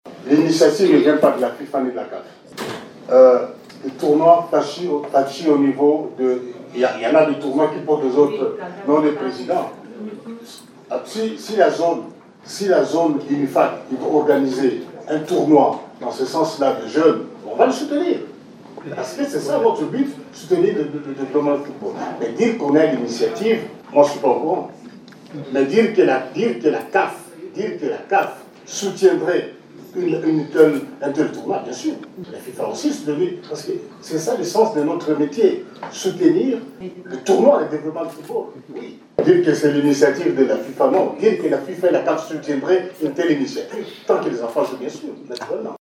Il a réagi au cours d’une conférence de presse tenue, une semaine après que le ministre des Sports, Kabulo Mwana Kabulo annonçait l’organisation et le financement de ce tournoi par l’organe faitier du football mondial.